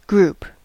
Ääntäminen
Ääntäminen US : IPA : [ɡɹuːp]